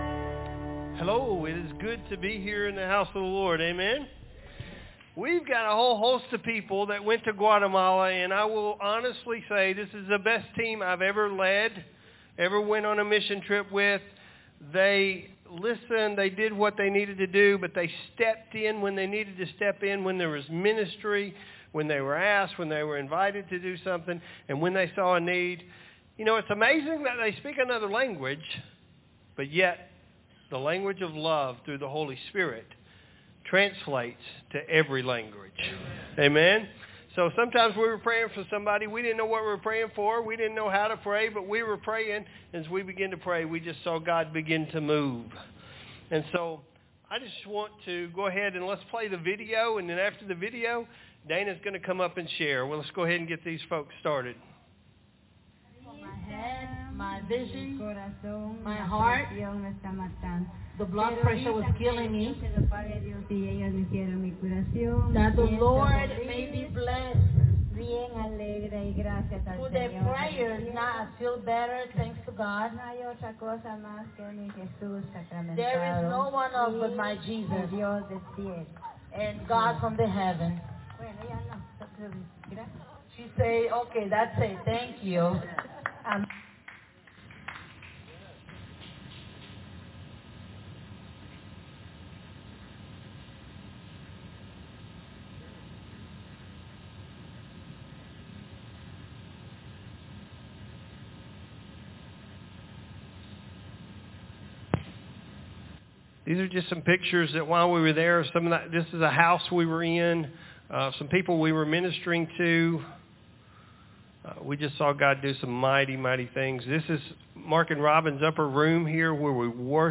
Guatemala Mission Trip Team Testimonies